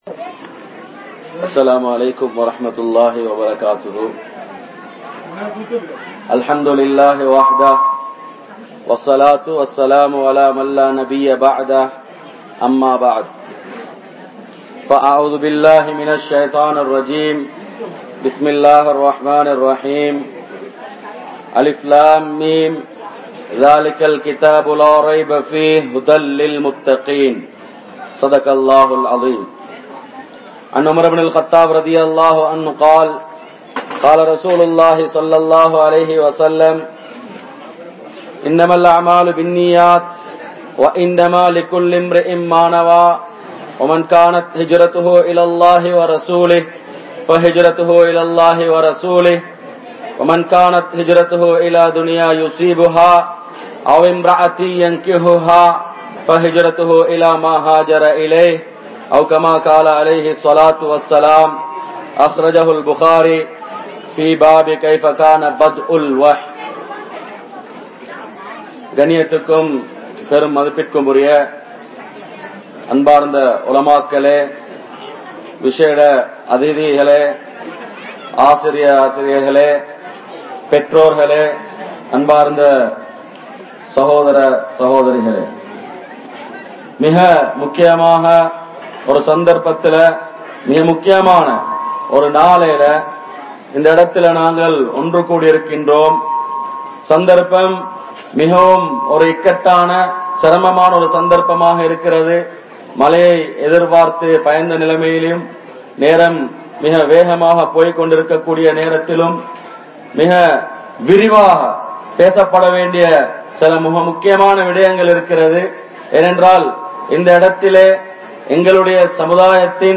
Allah`vin Payam Illaatha Indraya Maanavarhal (அல்லாஹ்வின் பயம் இல்லாத இன்றைய மாணவர்கள்) | Audio Bayans | All Ceylon Muslim Youth Community | Addalaichenai